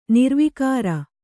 ♪ nirvikāra